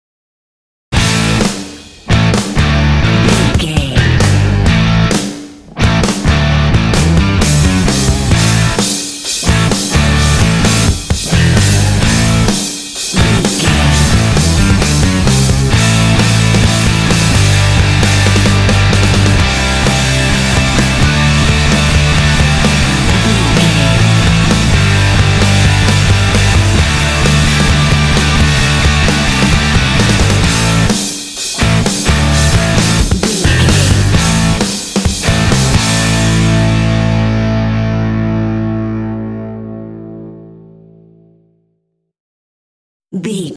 Epic / Action
Aeolian/Minor
powerful
energetic
heavy
electric guitar
drums
bass guitar
rock
heavy metal
classic rock